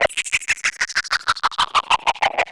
RI_ArpegiFex_95-01.wav